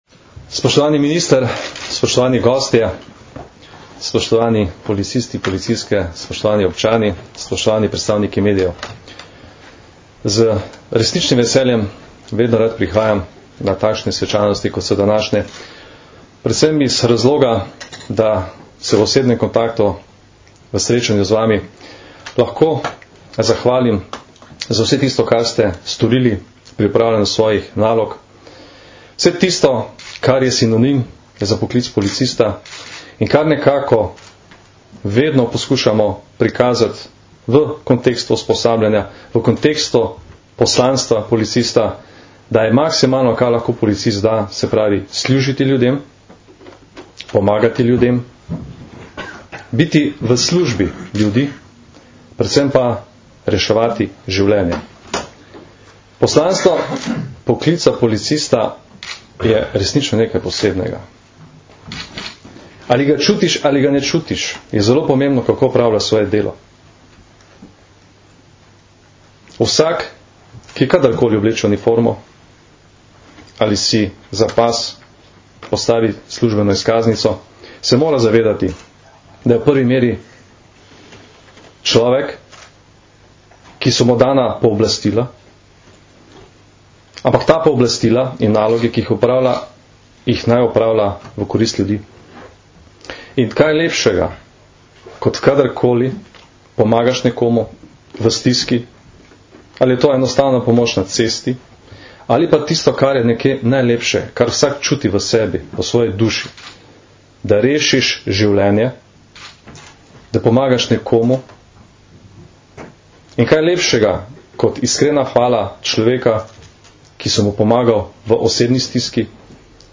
Generalni direktor policije Janko Goršek in minister za notranje zadeve dr. Vinko Gorenak sta danes, 13. junija 2012, v Policijski akademiji v Tacnu podelila 22 medalj policije za požrtvovalnost. Oba sta zbrane tudi nagovorila.
Zvočni posnetek nagovora generalnega direktorja policije (mp3)